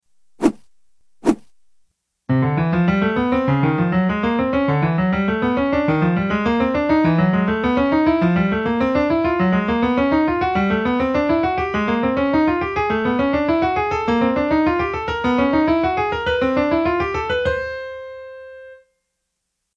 Dividing one scale between the hands is a great way to bypass muscle memory and truly test your musical understanding! Doing so breaks the major scale into two identical 4-note sequences (i.e. major tetrachords).